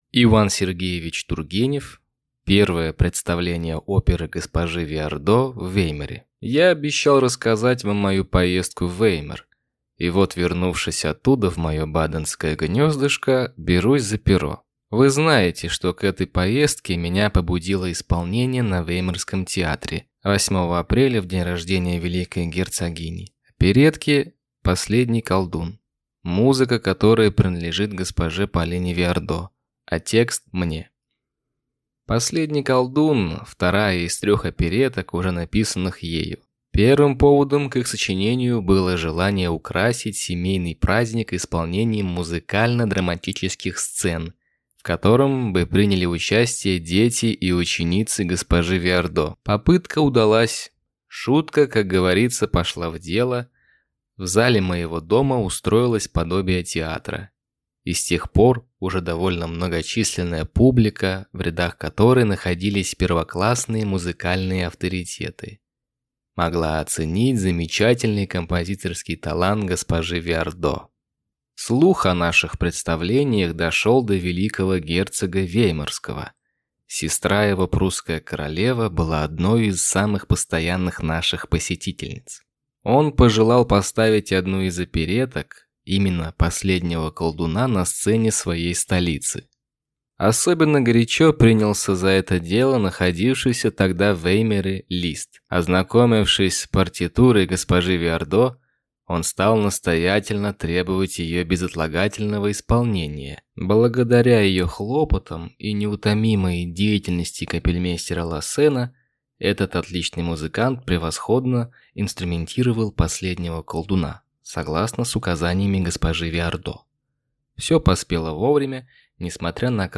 Аудиокнига Первое представление оперы г-жи Виардо в Веймаре | Библиотека аудиокниг